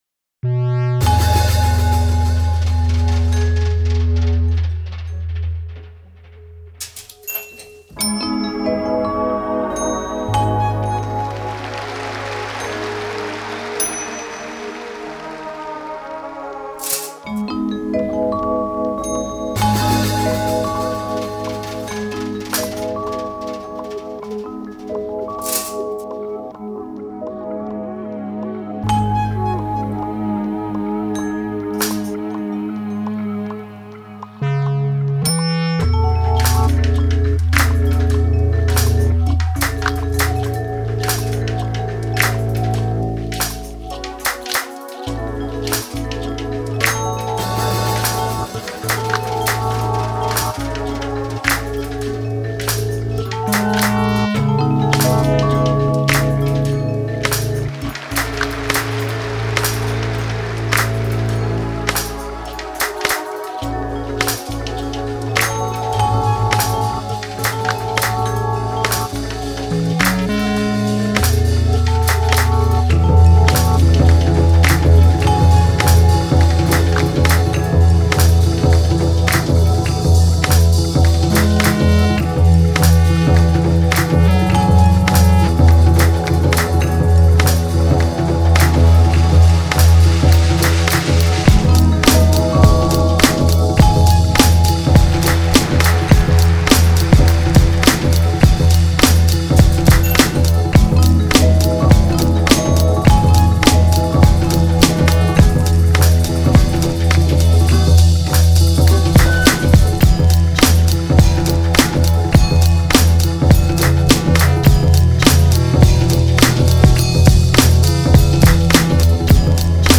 two brand new instrumentals